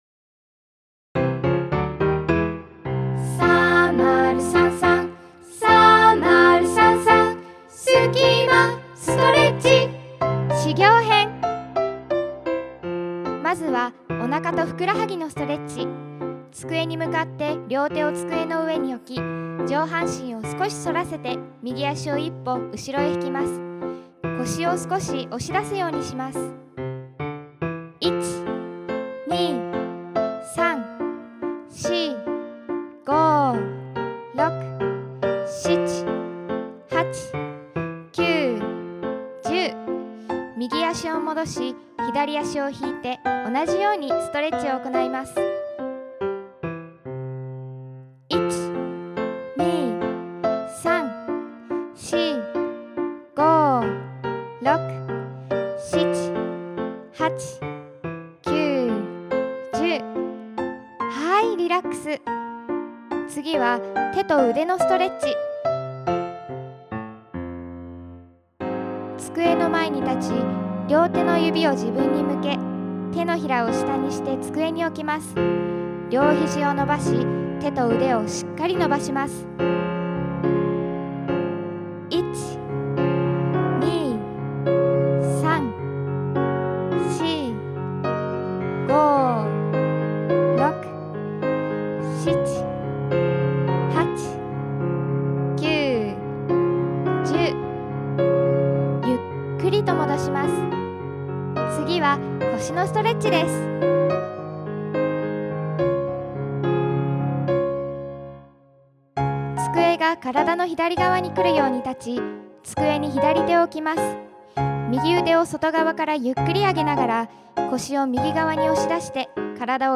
ガイドナレーション